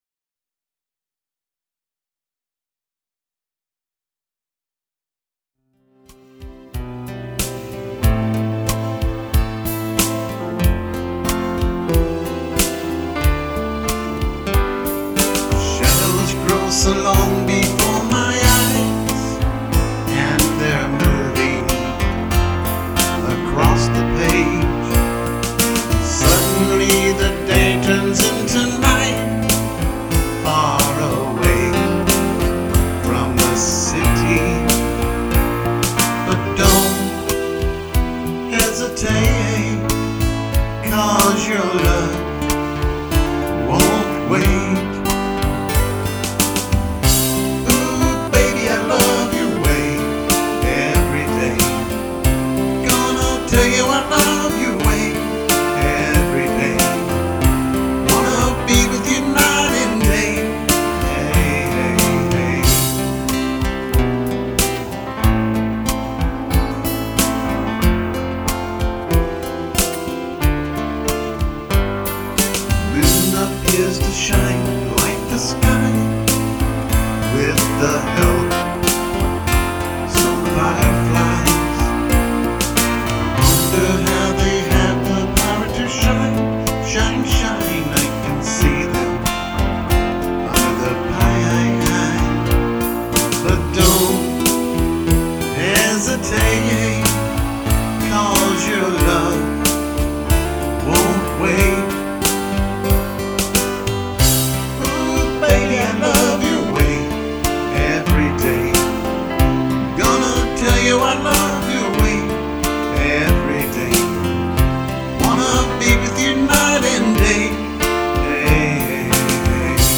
AT THE STAE FAIR OF TEXAS 2010